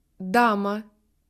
Consonnes
Écoutez la différence en russe sur ces quelques exemples:
"DUR"